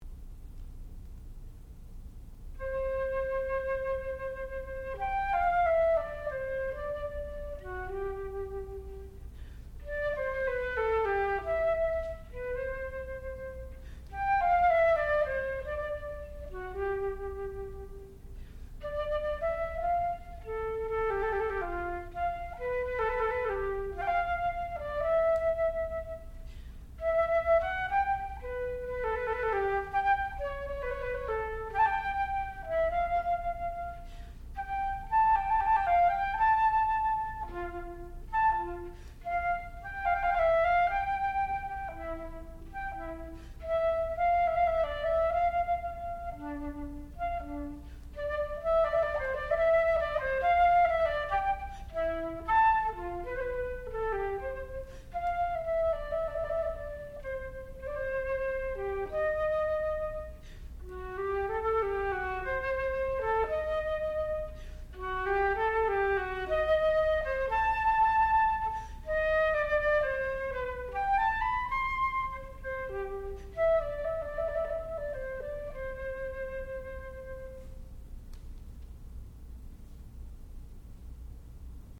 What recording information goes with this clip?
Advanced Recital